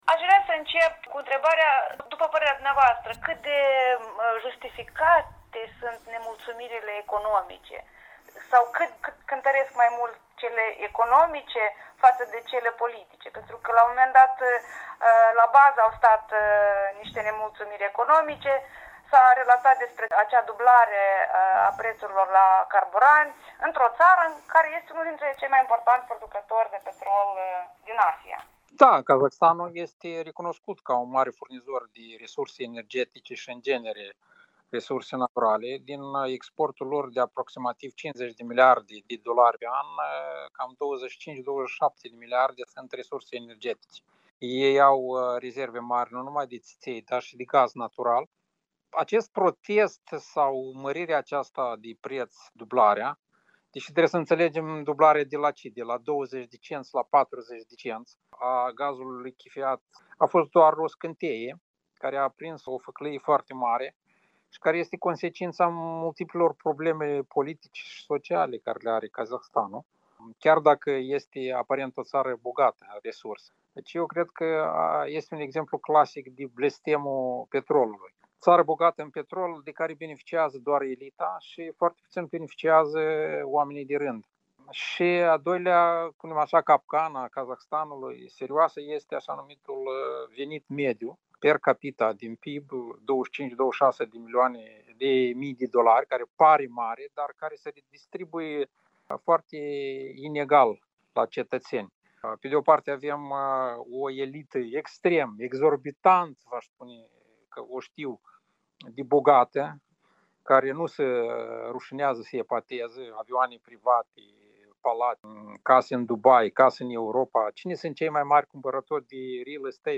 Interviu cu Ion Sturza despre situatia din Kazahstan